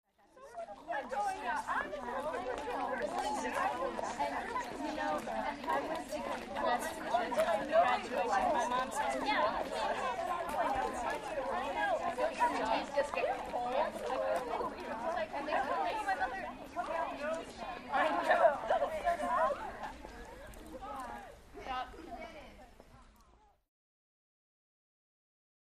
Teenage Girls Walking By, W Giggling And Talking Gossip.